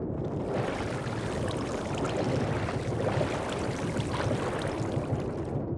Minecraft Version Minecraft Version 25w18a Latest Release | Latest Snapshot 25w18a / assets / minecraft / sounds / minecart / inside_underwater3.ogg Compare With Compare With Latest Release | Latest Snapshot
inside_underwater3.ogg